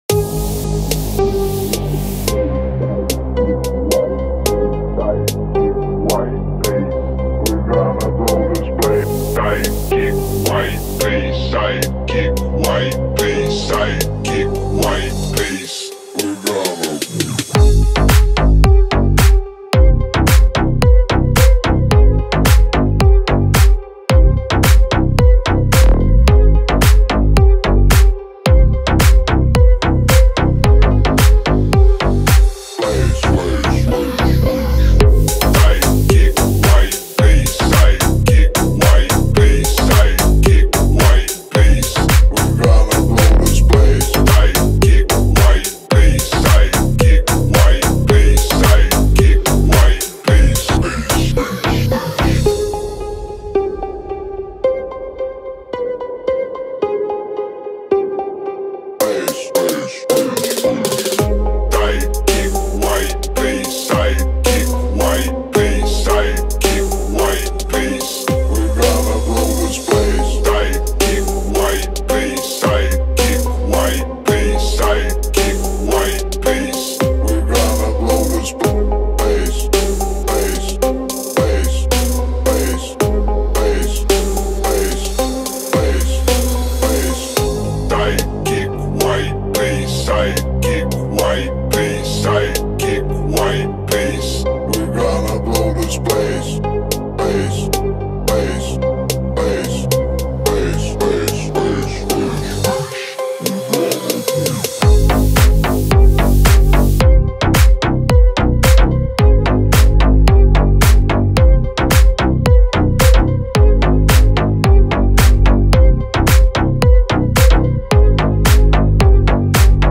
это атмосферная композиция в жанре электронной музыки